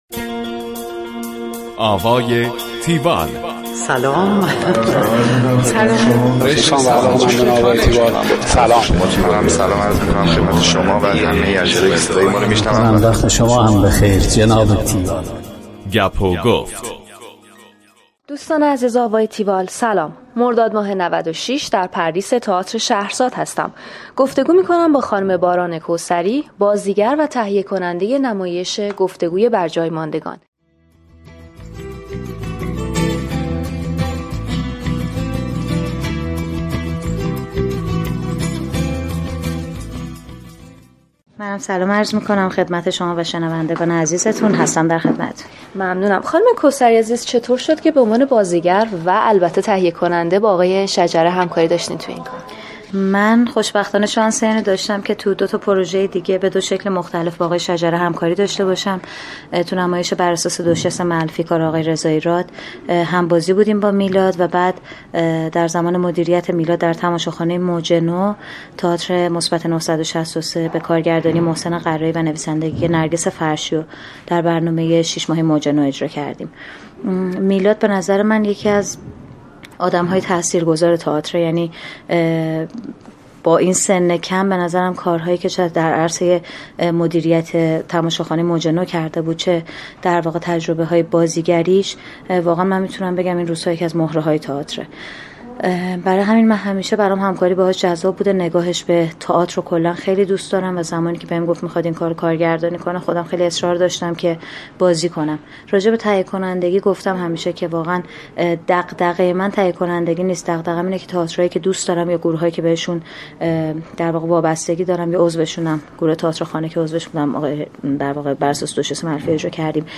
گفتگوی تیوال با باران کوثری
tiwall-interview-barankosari.mp3